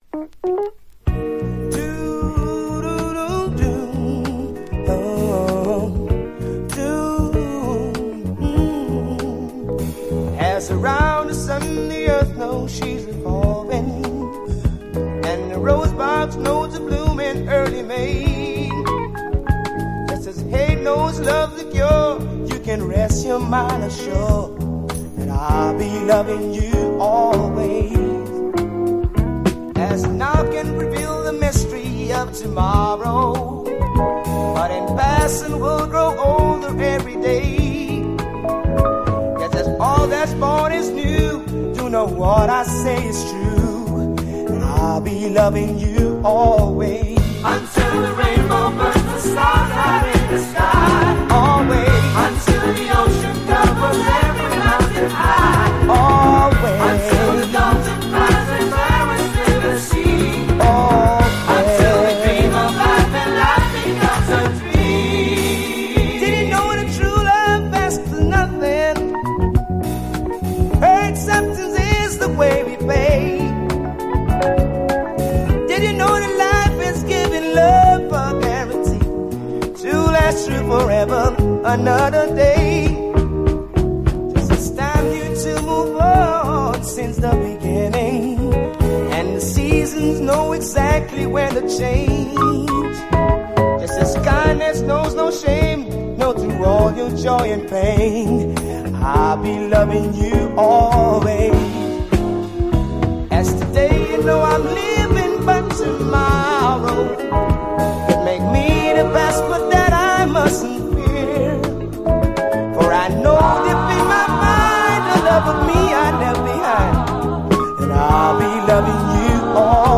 70’Sソウルの良心を感じつつレアグルーヴな魅力も有り！
伸びやかな女性コーラスのラヴリー・ソウル
ドラム・ブレイクから始まるスムース・ブギー・ダンサー
スリリングなメロウ・ファンク